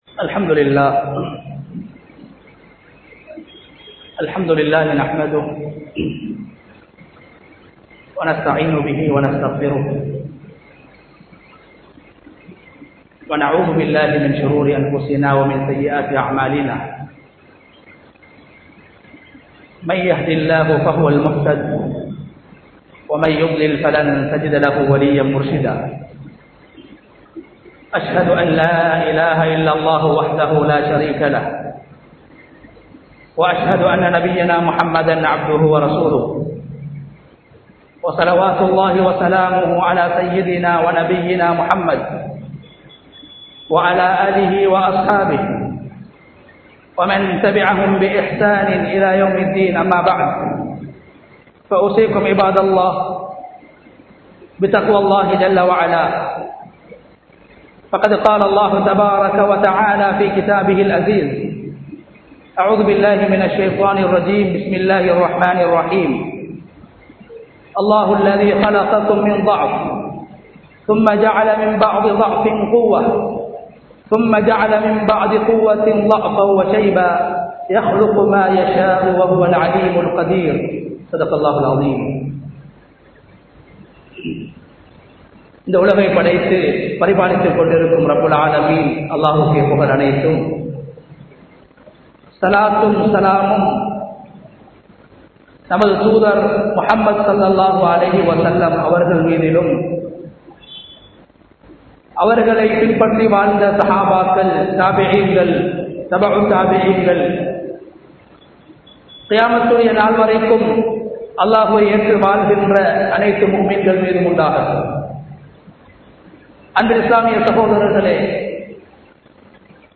முதியோர்களை மதிப்போம் | Audio Bayans | All Ceylon Muslim Youth Community | Addalaichenai
Wattala, Mabola, Arachiwatta, Rabbaniyah Jumua Masjidh 2022-08-12 Tamil Download